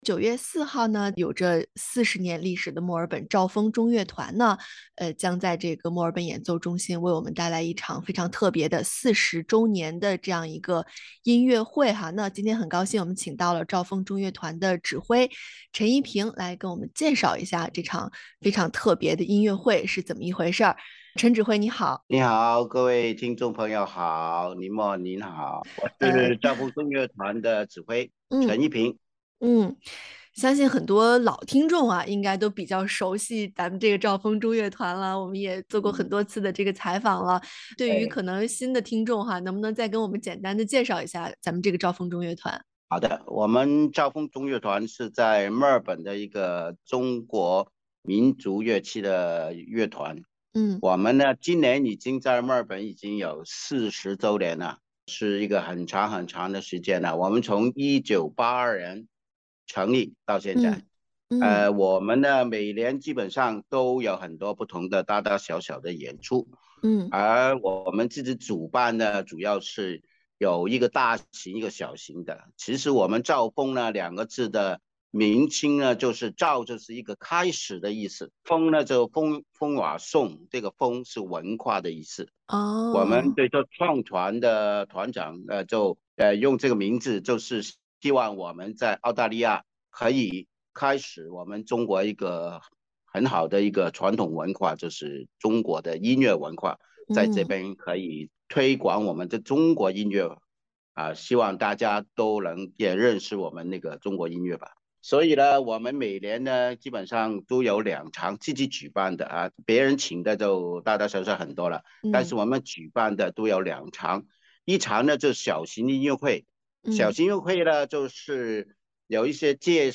在采访中